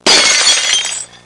Breaking Glass Sound Effect
Download a high-quality breaking glass sound effect.
breaking-glass.mp3